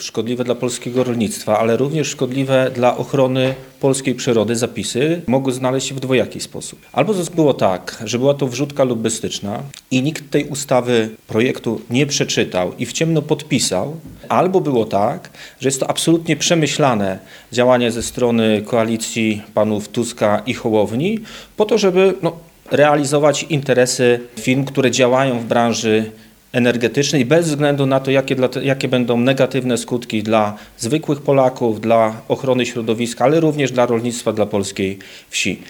O rzekomym udziale lobbystów w pracach nad tzw. ustawą wiatrakową podczas dzisiejszej konferencji prasowej mówili posłowie Prawa i Sprawiedliwości.